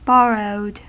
Ääntäminen
Ääntäminen US Tuntematon aksentti: IPA : /ˈbɒrəʊd/ Haettu sana löytyi näillä lähdekielillä: englanti Käännös Adjektiivit 1. entlehnt Borrowed on sanan borrow partisiipin perfekti.